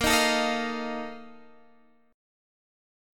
BbM7sus4 chord